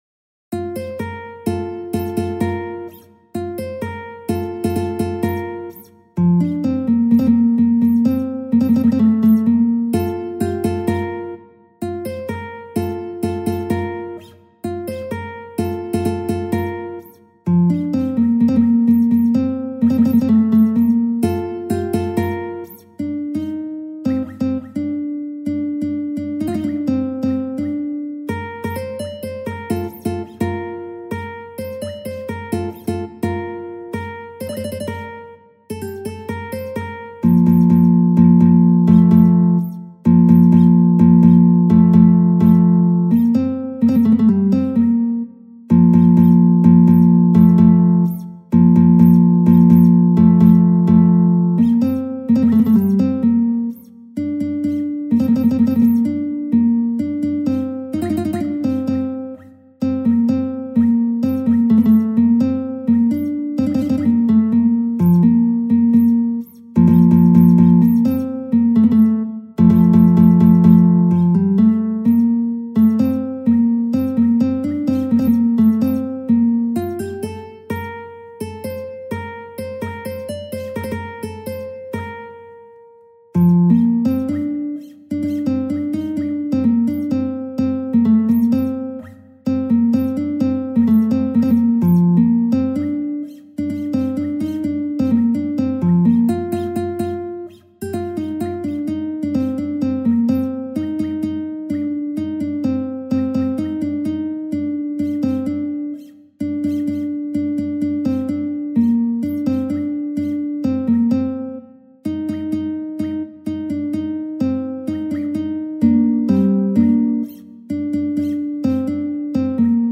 تنظیم شده برای گیتار